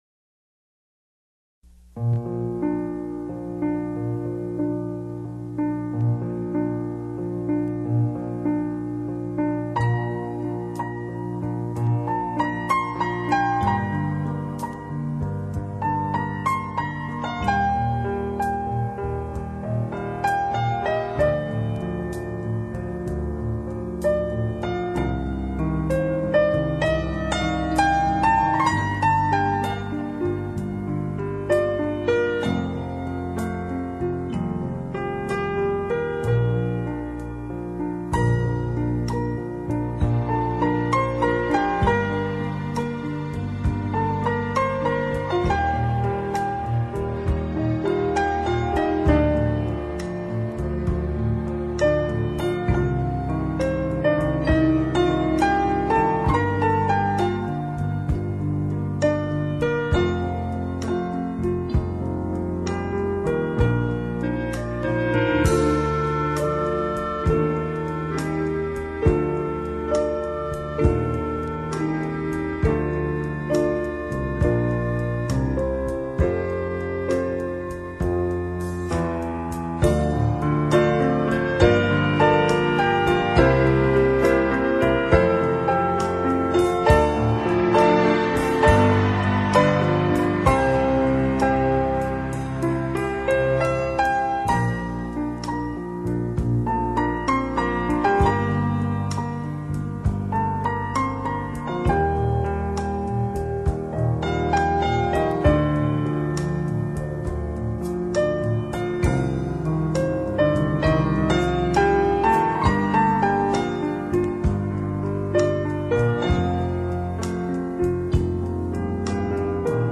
2/   Romantické melódie
upravené pre hru na piano